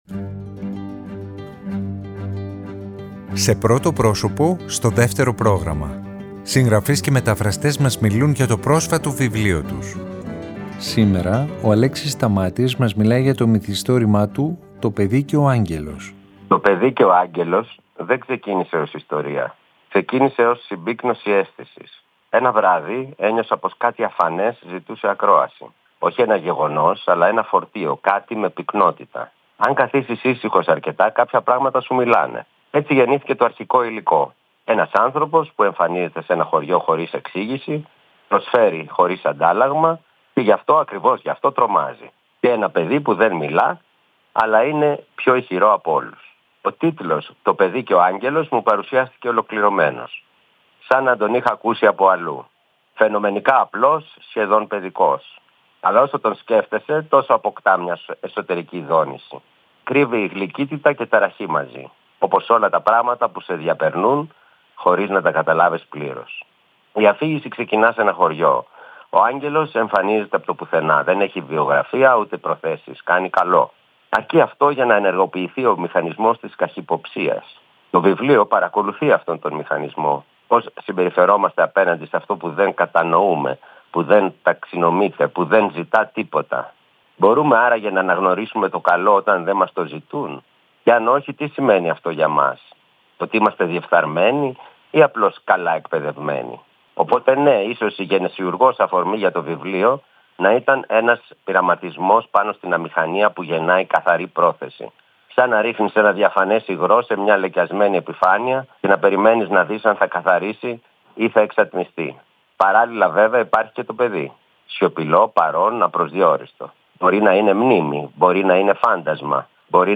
Συγγραφείς και μεταφραστές μιλάνε
Σήμερα Δευτέρα ο Αλέξης Σταμάτης μας μιλάει για το μυθιστόρημα του “Το Παιδί και ο Άγγελος”.